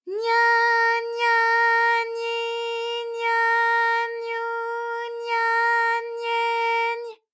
ALYS-DB-001-JPN - First Japanese UTAU vocal library of ALYS.
nya_nya_nyi_nya_nyu_nya_nye_ny.wav